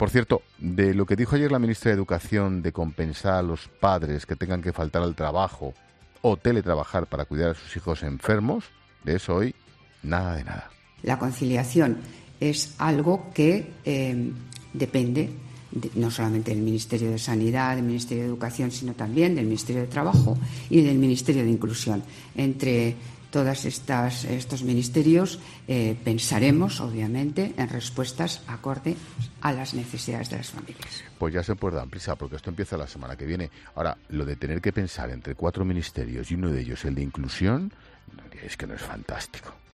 En su monólogo de las 19h, el presentador de 'La Linterna', Ángel Expósito ha aprovechado las declaraciones de la ministra de Educación, Isabel Celaá sobre si los padres deben de ser los responsables de detirminar si sus hijos deben o no ir al colegio si estos presentan síntomas de coronavirus y si lo padecen, compensarles por si tienen que faltar al trabajo o teletrabajar para poder estar en casa con sus hijos.